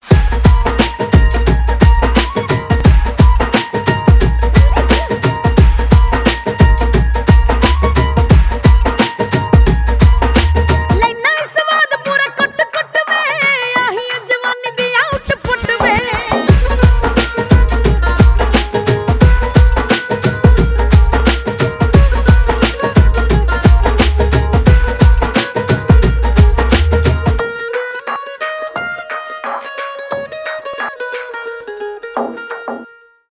поп , зарубежные , индийские